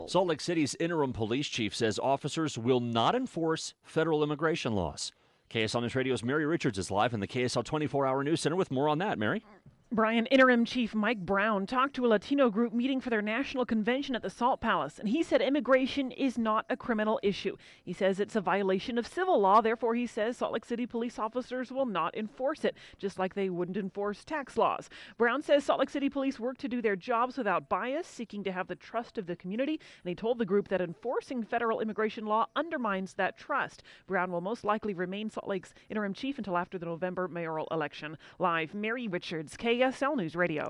Mike Brown spoke at the League of United Latin American Citizens National Convention Unity Luncheon in Salt Lake City.